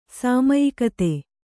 ♪ sāmayikate